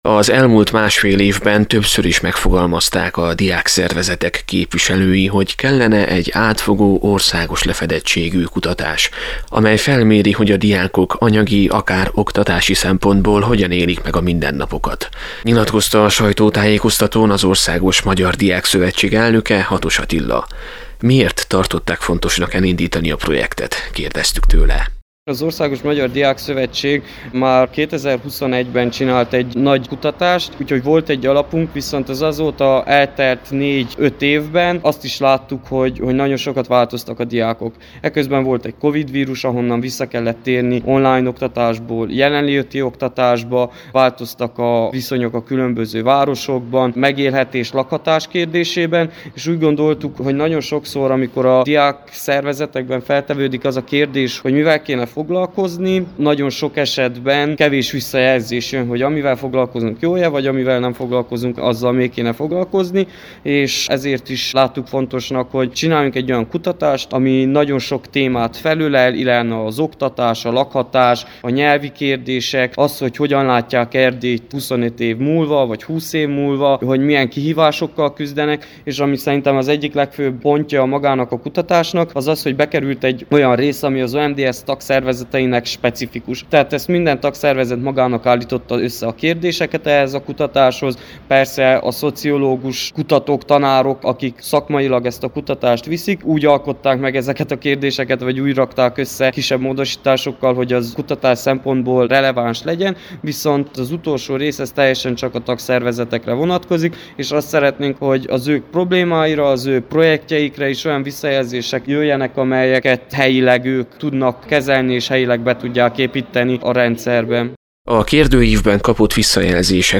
A részletekről a KMDSZ új székházában tartott sajtótájékoztatón számoltak be a projekt vezetői.
a helyszínen készített összeállítást.